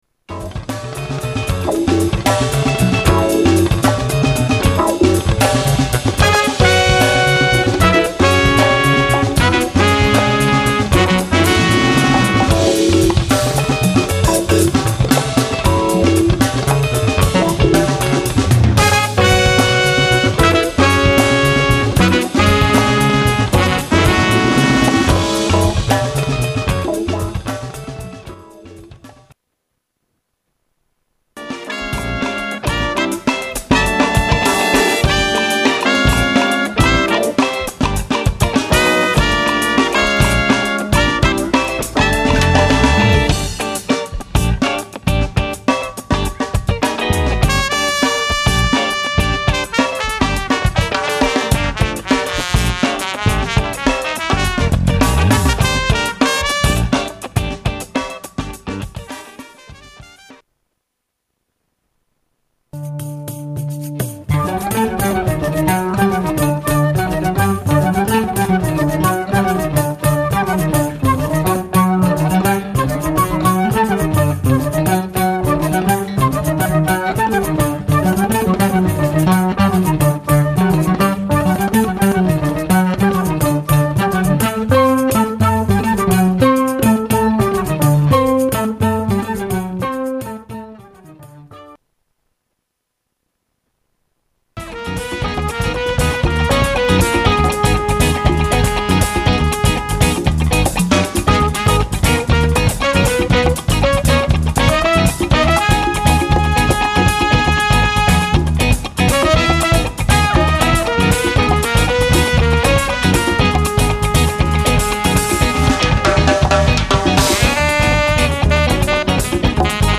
at Herbert Hall in Salzburg
sax
flute
fender rhodes, moog
bass
percussion
drums